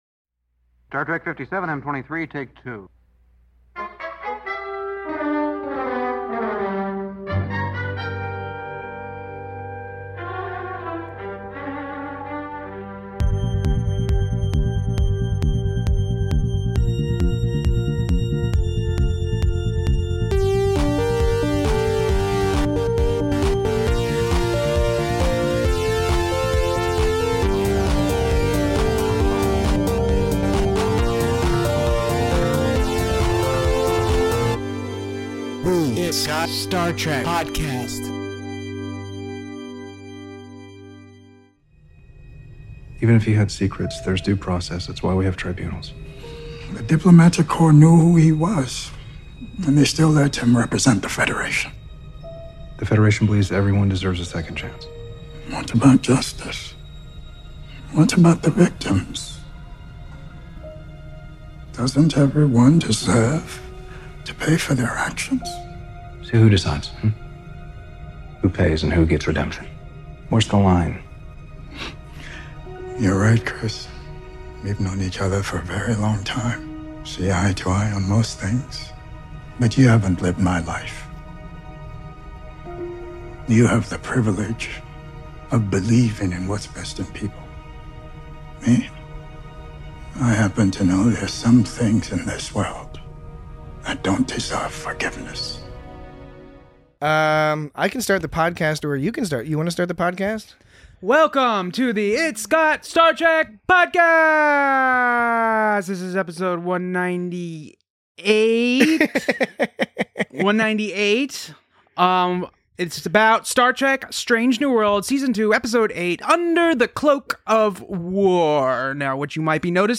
Join two-thirds of your hosts as they discuss the terrors of PTSD, powerful performances, and somewhat confusing plot elements in an otherwise excellent episode of Star Trek: Strange New Worlds.